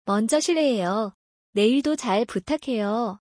モンジョ シレヘヨ. ネイド チャ プタケヨ